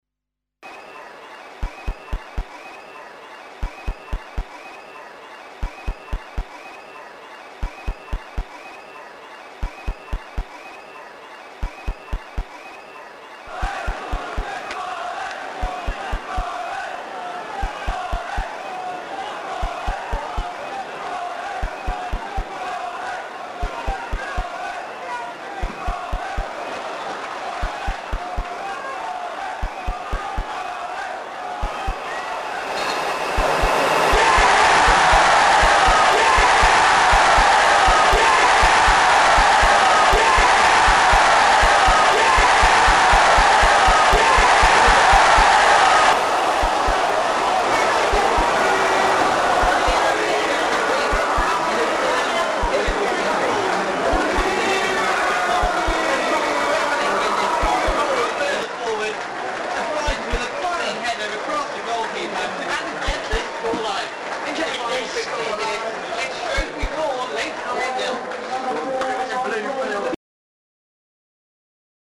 the final season at Gay Meadow, Shrewsbury Football Club sound installation